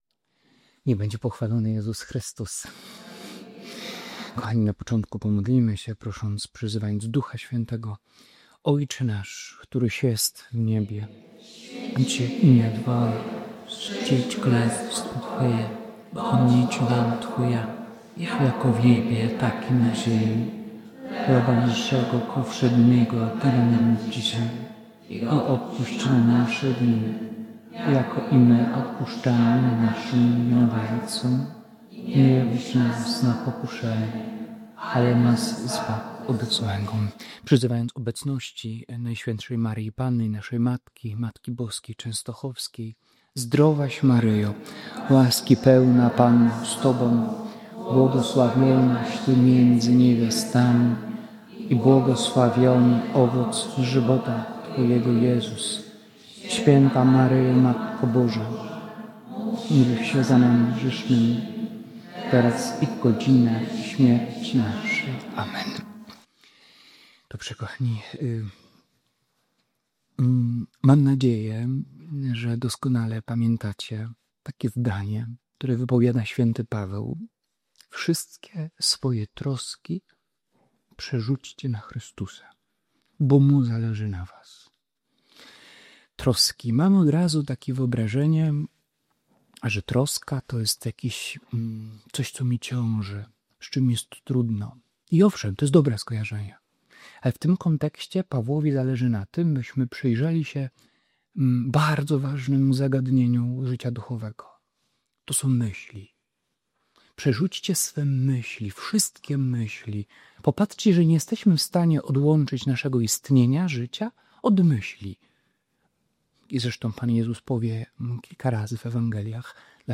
Kapłan pokazuje, że chrześcijanin nie ma żyć w chaosie rozproszeń i zamartwiania się, ale w czujności, wolności serca i ufności Jezusowi. W centrum rozważań pojawia się wezwanie, by wszystkie troski i wewnętrzny niepokój oddawać Chrystusowi, który pragnie obdarzyć człowieka swoim miłosierdziem i pokojem. To poruszająca konferencja o walce duchowej, modlitwie serca i prostym zawierzeniu: „Jezu, Ty się tym zajmij”.